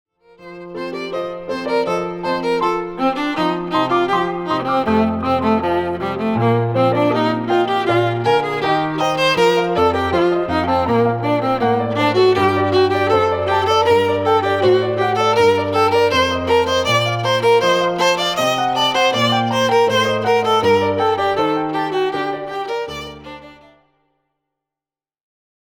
groep5_les1-4-2_strijkinstrumenten4.mp3